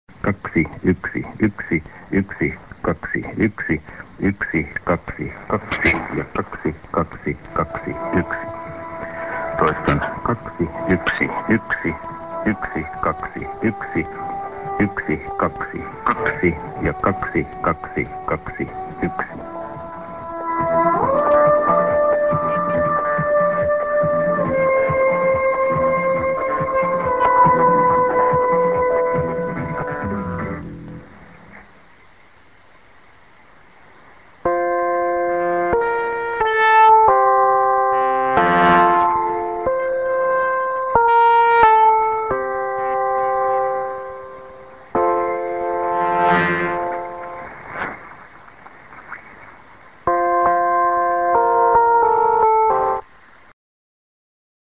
a:　End SKJ(man)->34":IS